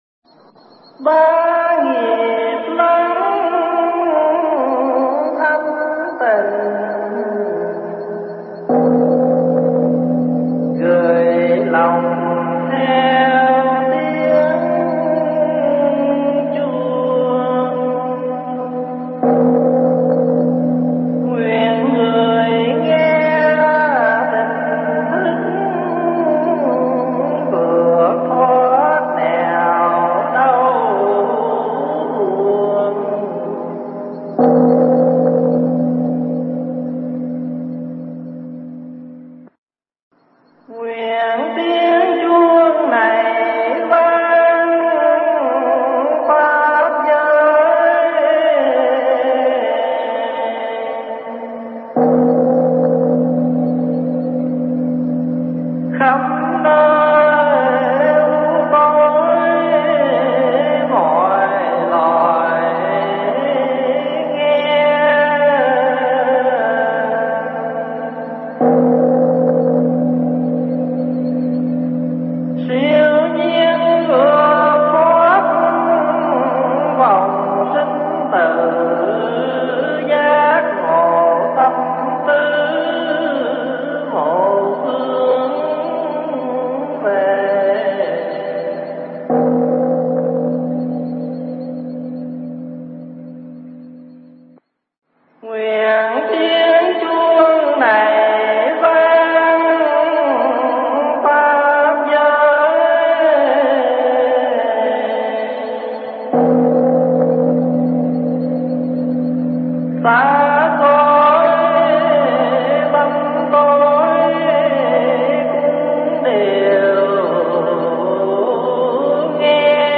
Nghe Mp3 thuyết pháp Bồ Tát Tại Gia Phần 38
giảng tại Tu viện Tây Thiên, Canada